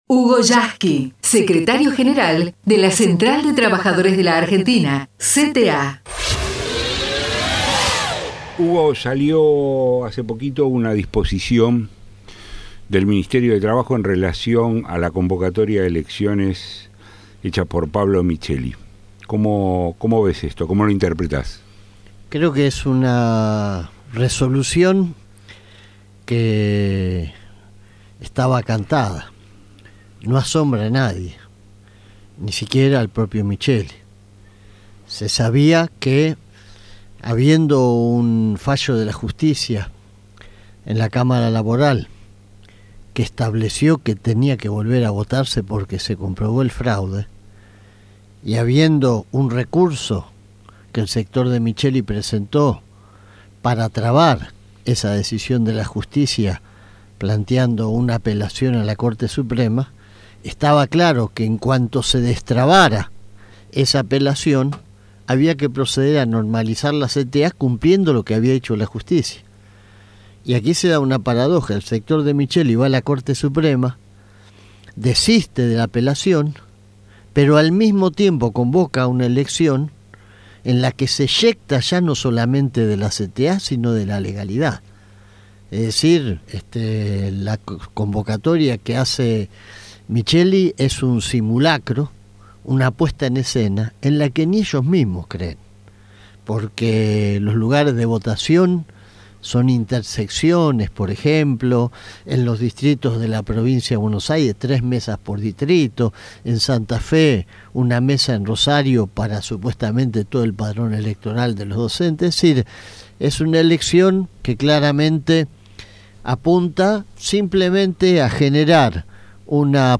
El secretario General de la CTA en RADIO CENTRAL